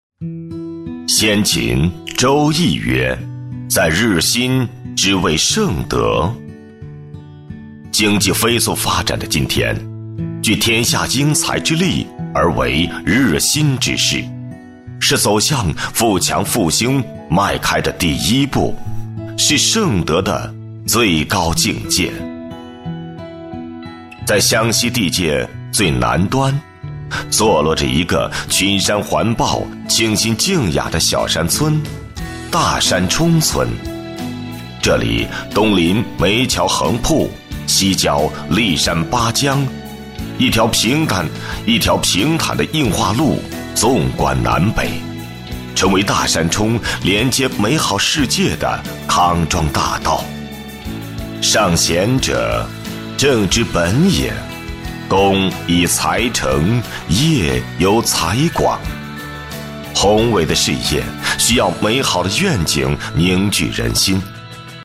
自然诉说 企业专题
磁性男中音，厚重、激情有力。